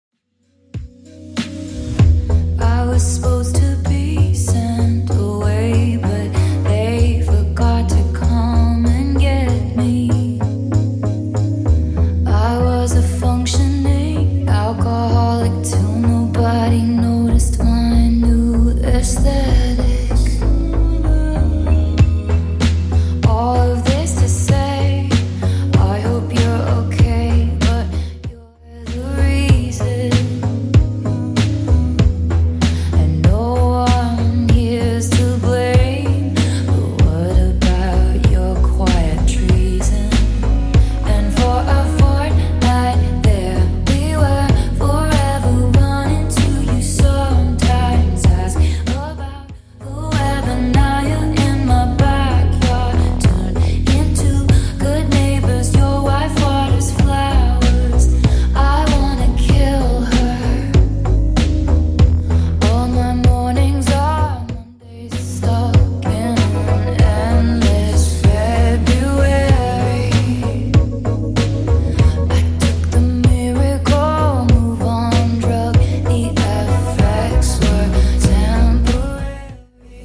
Genre: ROCK
BPM: 128